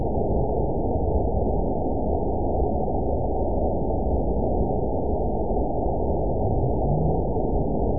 event 912016 date 03/15/22 time 14:12:54 GMT (3 years, 2 months ago) score 6.64 location TSS-AB04 detected by nrw target species NRW annotations +NRW Spectrogram: Frequency (kHz) vs. Time (s) audio not available .wav